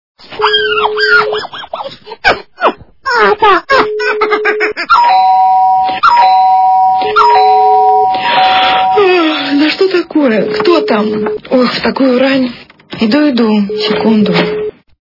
При прослушивании Крики животных ы звонок - Кто там в такую рань?... качество понижено и присутствуют гудки.
Звук Крики животных ы звонок - Кто там в такую рань?...